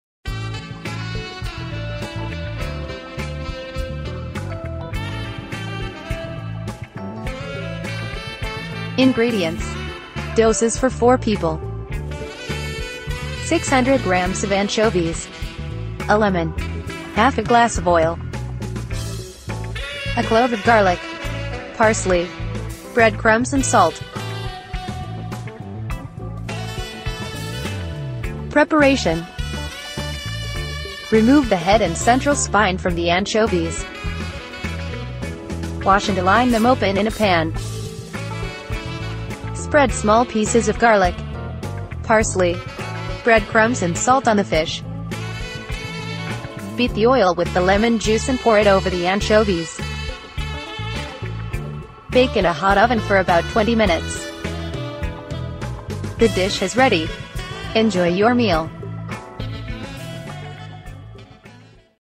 Un racconto settimanale dedicato agli "invisibili", agli outsider, a chi nuota controcorrente: vite strane, curiosità dimenticate, personaggi di provincia, ribelli silenziosi e storie che sembrano di contorno, ma lasciano il segno. Con uno stile narrativo diretto ma poetico, Acciughe Gratinate invita l’ascoltatore a guardare il mondo da un’angolazione diversa — più bassa, più vera, più saporita.